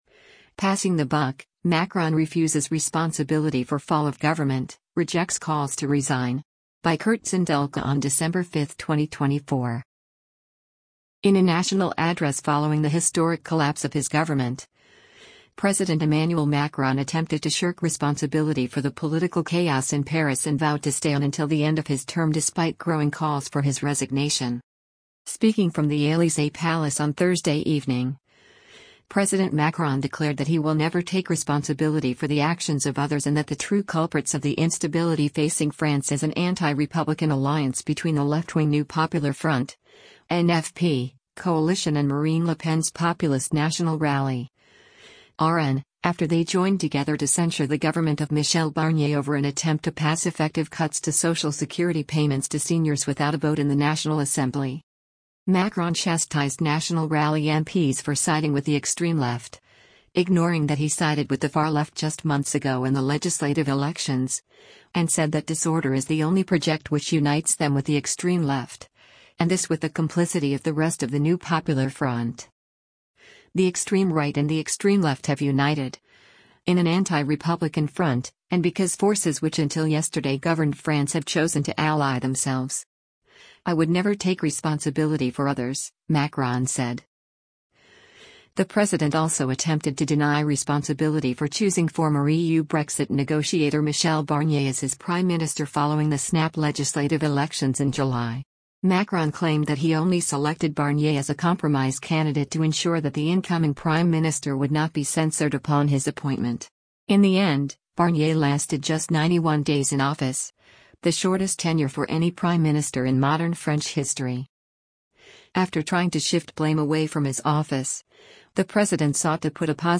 In a national address following the historic collapse of his government, President Emmanuel Macron attempted to shirk responsibility for the political chaos in Paris and vowed to stay on until the end of his term despite growing calls for his resignation.